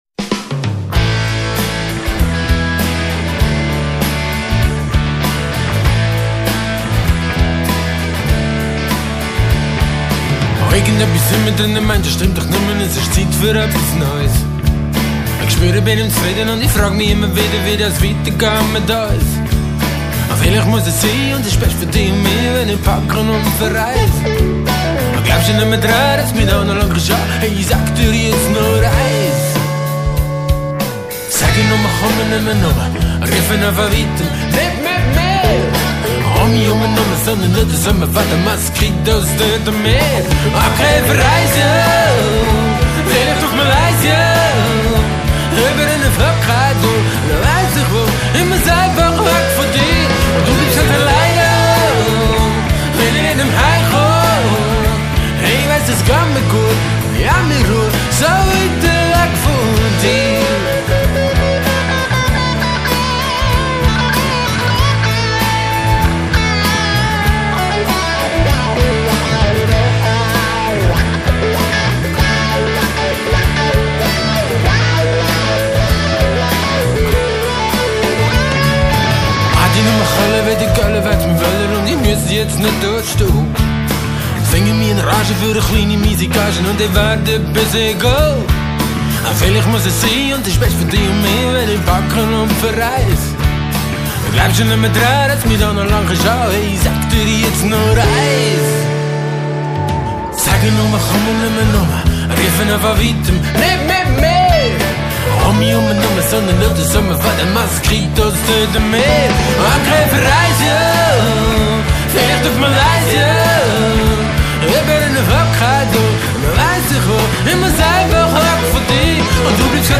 Der Song ist ironisch und auch ein wenig Parodie.
Schlagzeug
Gesang, Bass, akustische Gitarre, E-Rhythmusgitarre
E-Gitarre und Solo
Chorgesang